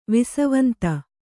♪ visavanta